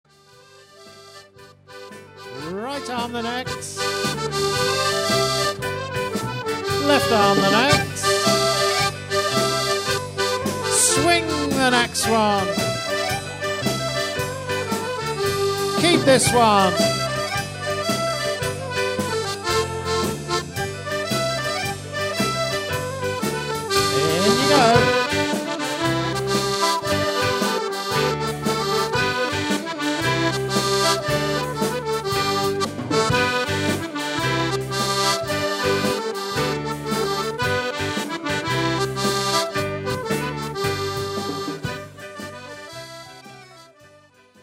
If you have a fast link, or are very patient, you can hear some short MP3 samples of the band from recent ceilidhs by clicking the dancing logos below.
recorded at Four Oaks in August 2004